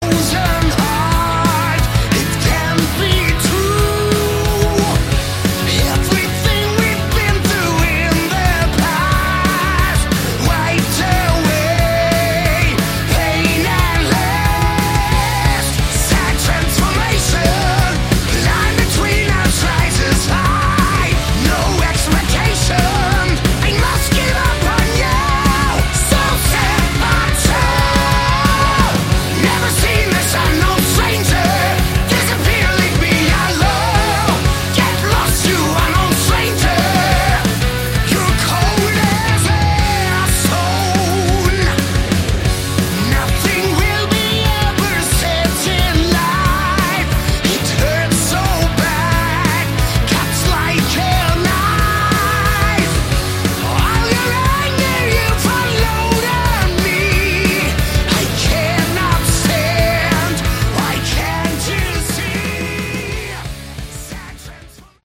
Category: Hard Rock
guitars
drums
vocals
bass
keyboards